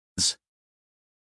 us_phonetics_sound_friends_2023feb.mp3